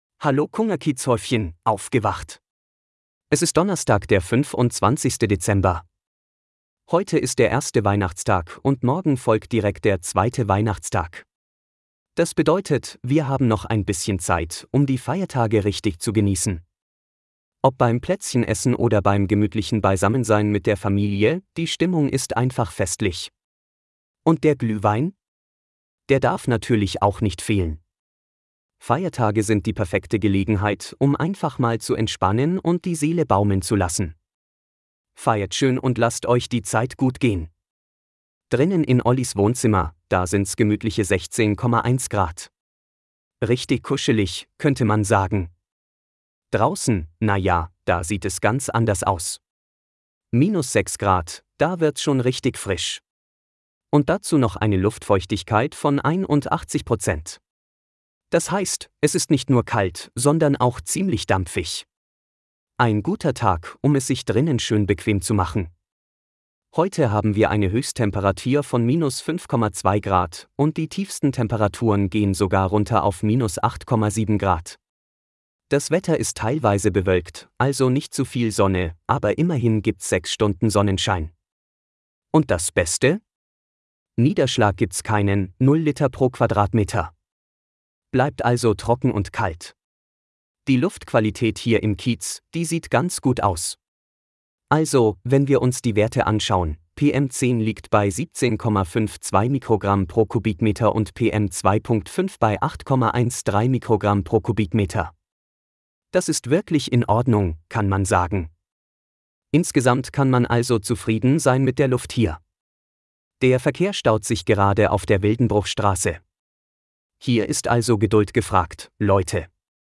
Automatisierter Podcast mit aktuellen Wetter-, Verkehrs- und Geburtstagsinfos.